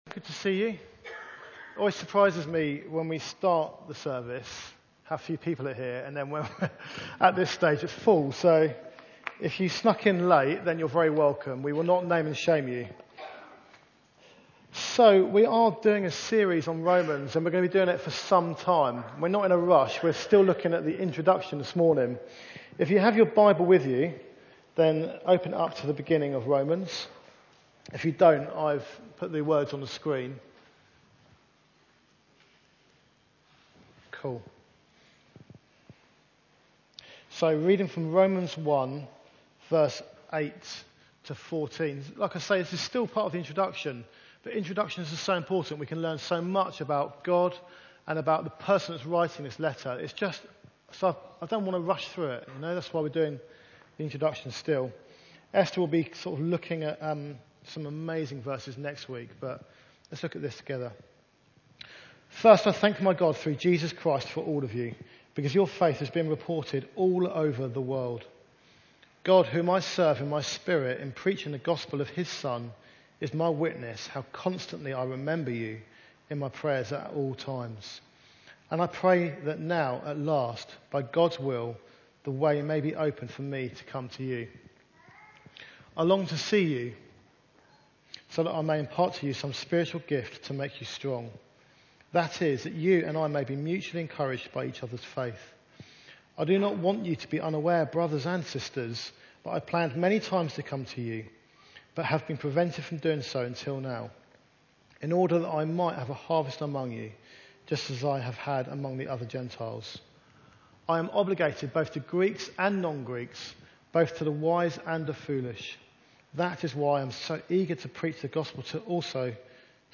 Service Type: Sunday Meeting